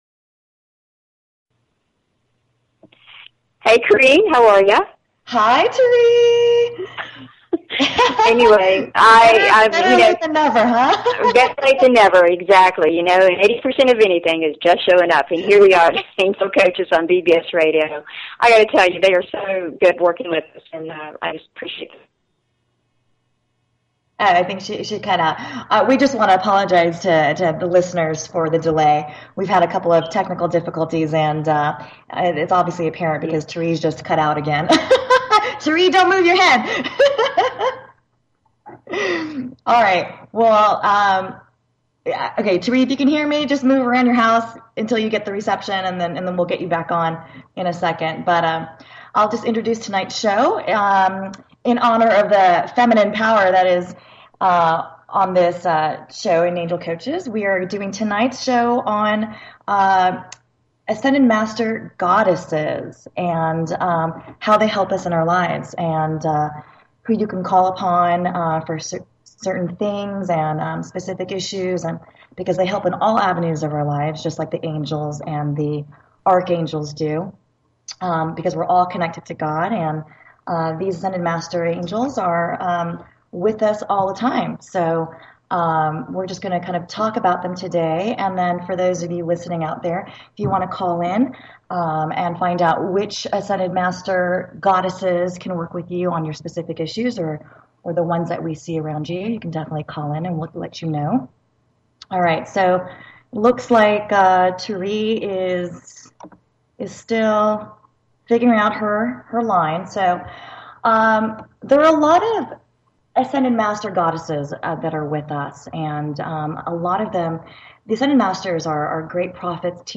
Talk Show Episode, Audio Podcast, Angel_Coaches and Courtesy of BBS Radio on , show guests , about , categorized as